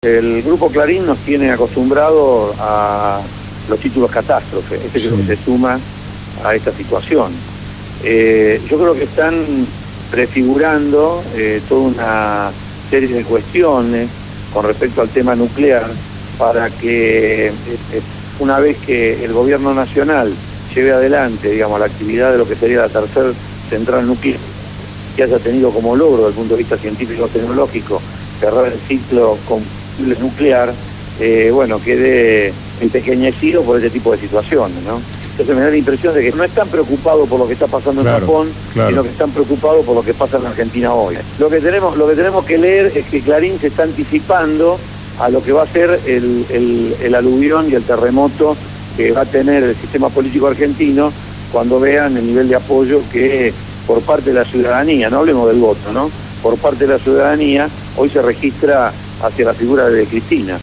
Fueron los dichos de Abel Fatala, Subsecretario de Obras Públicas de la Nación que fue entrevistado en el programa «Abramos la boca» (Lunes a viernes 16 a 18hs.) por Radio Gráfica.